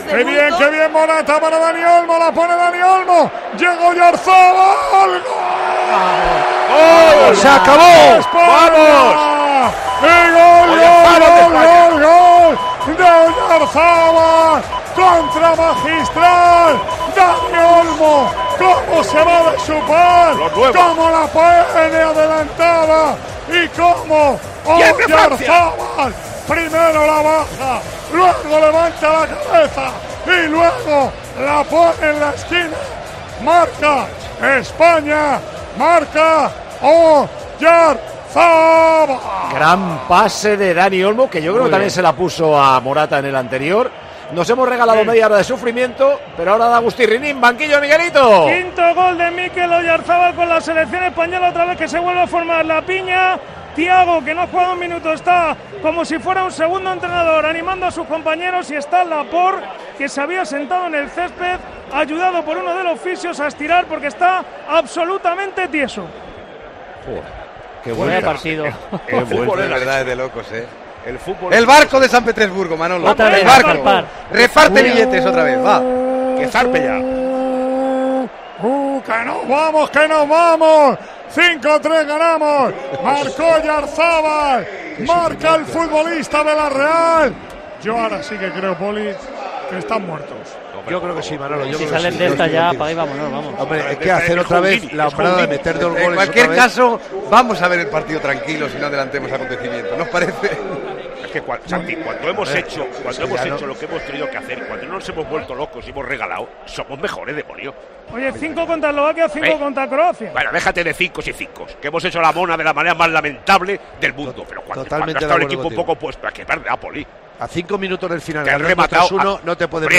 ASÍ NARRÓ MANOLO LAMA LOS GOLES DE CROACIA, 5 - ESPAÑA, 3